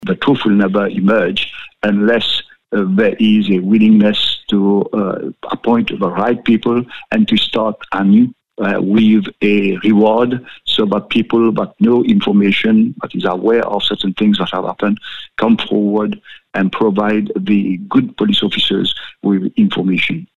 Former attorney general of Mauritius, Rama Valayden, says a fresh look at the case is needed: